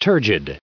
1029_turgid.ogg